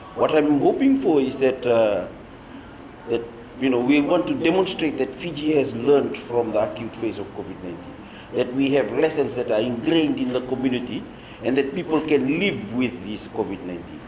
Permanent Secretary for Health, Doctor James Fong says the idea of ‘living with COVID’ will enable people to do the right thing at the right time.